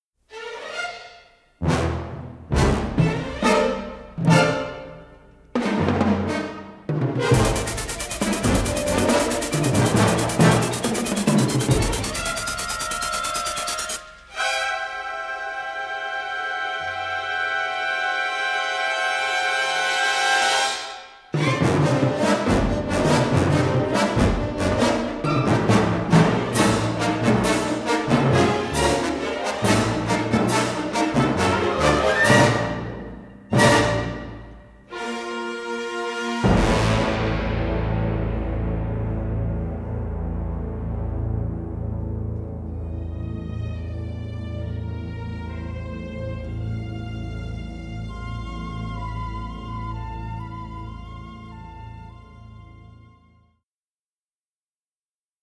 Unusually crisp, punchy recording